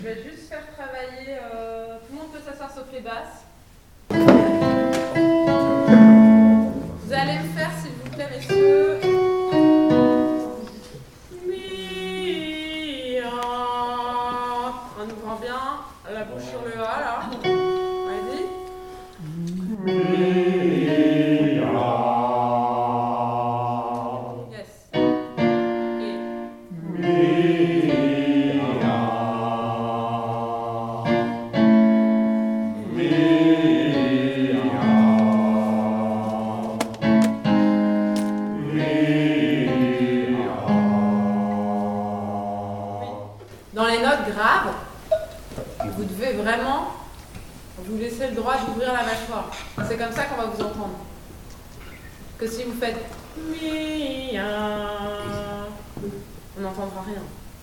Echauffement vocal
Ces enregistrements au format MP3 ont été réalisés lors de la répétiton du 22 septembre 2025.
Bonus pour voix T&B (ténor et basse) Echauffement 3 - La licorne (voix hommes)
3 - LA LICORNE -  Pour les chanteurs, un exercice efficace consiste à pratiquer des vocalises, soit des gammes ascendantes et descendantes, en utilisant des voyelles telles que "A," "E" et "O".